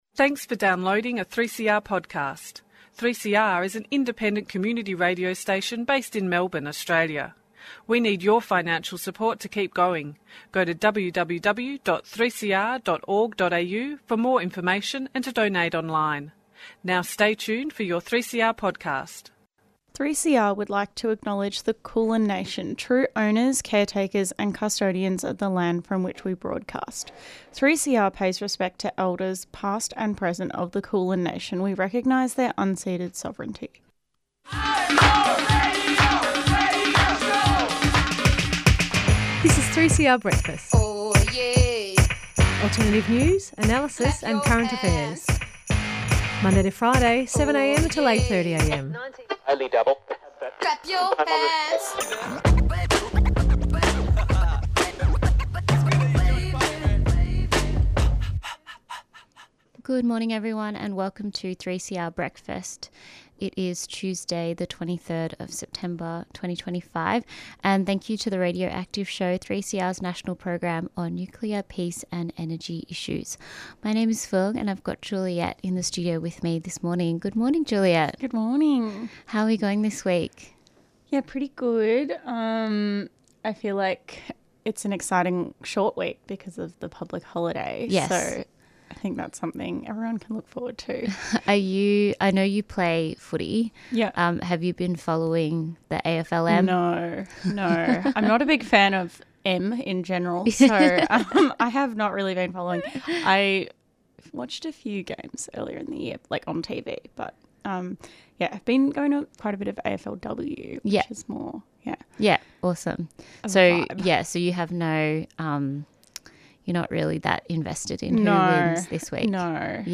On Sunday the 21st of September, various Palestine liberation groups rallied together for the 101st protest against Israel and Australia's complicity in the genocide since the onslaught began in October 2023.